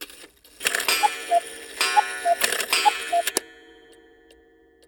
cuckoo-clock-03.wav